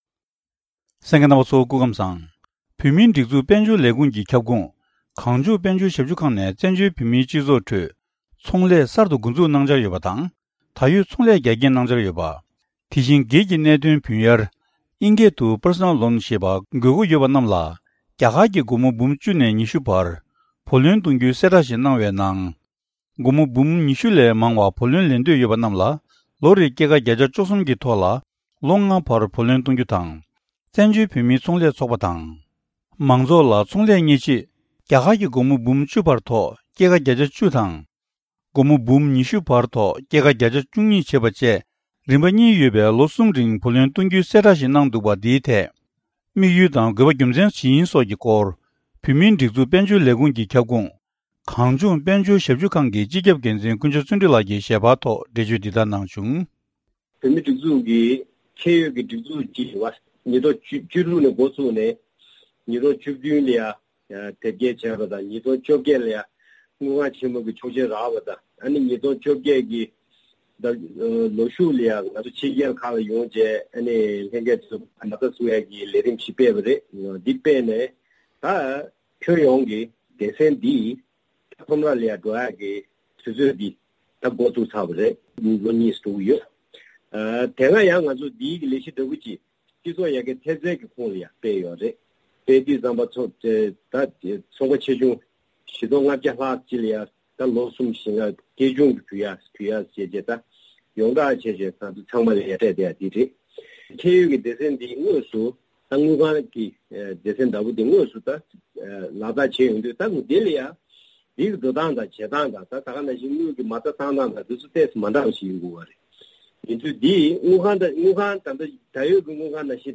བཀའ་འདྲི་ཞུས་ནས་ཕྱོགས་སྒྲིག་ཞུས་པ་ཞིག་གསན་རོགས་གནང་།།